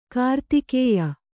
♪ kartikēya